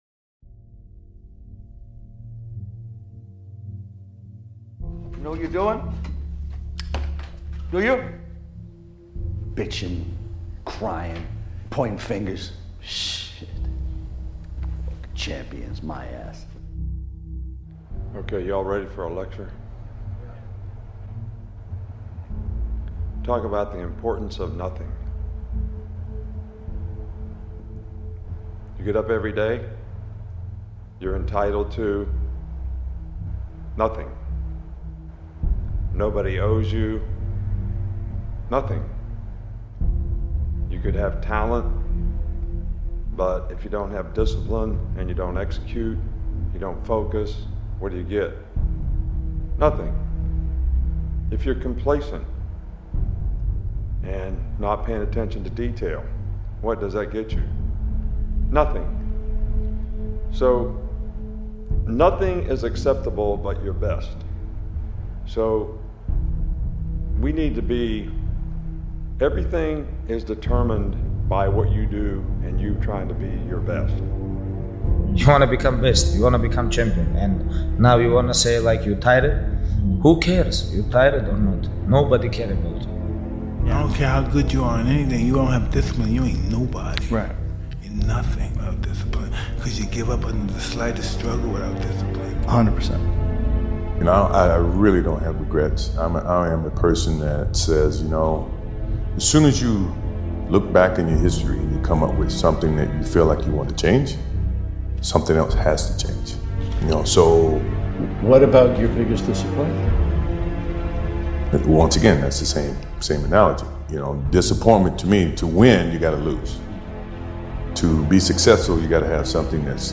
Become Disciplined | Powerful Motivational Speech